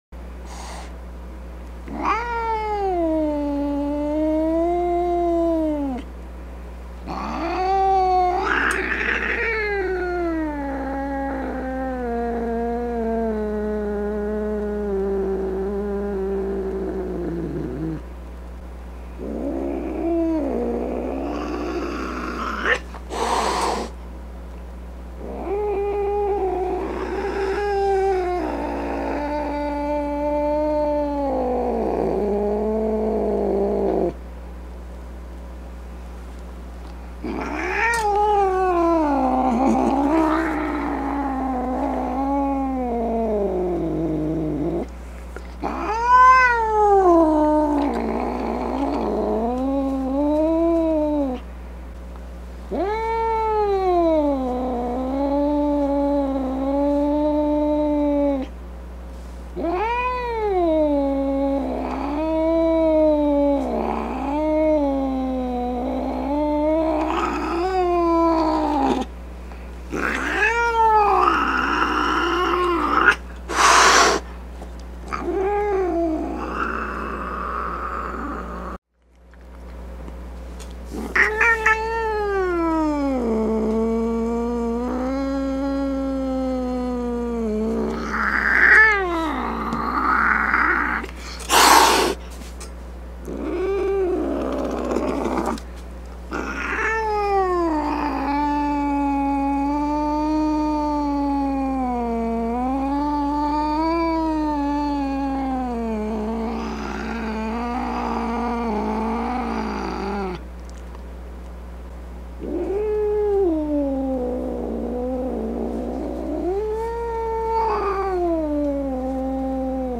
جلوه های صوتی
دانلود صدای گارد گرفتن گربه از ساعد نیوز با لینک مستقیم و کیفیت بالا
برچسب: دانلود آهنگ های افکت صوتی انسان و موجودات زنده دانلود آلبوم صدای گربه عصبانی از افکت صوتی انسان و موجودات زنده